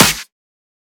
edm-clap-49.wav